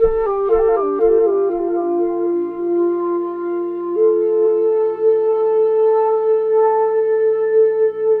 Flute1_117_D.wav